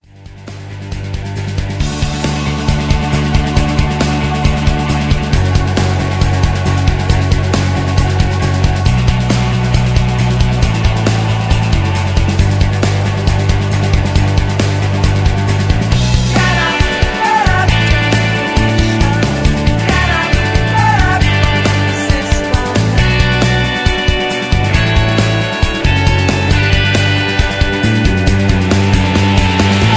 Karaoke Mp3 Backing Tracks